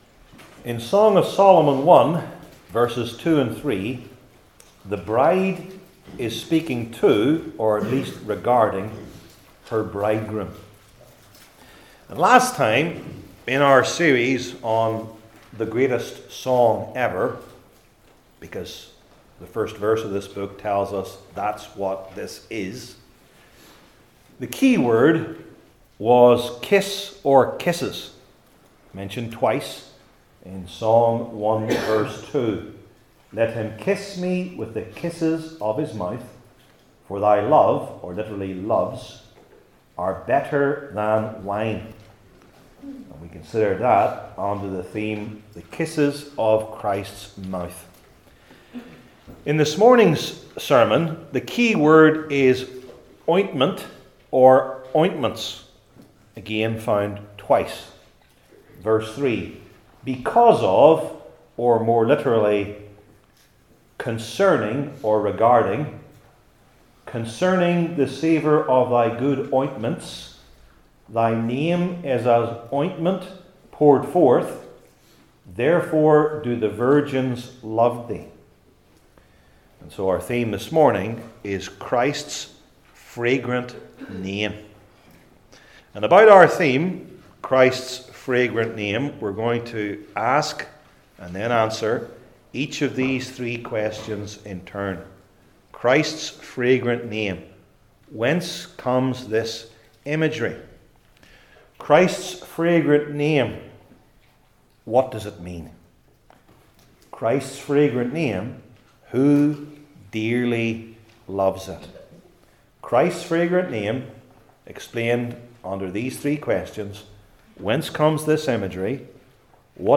Old Testament Sermon Series I. Whence Comes This Imagery?